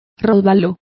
Also find out how robalo is pronounced correctly.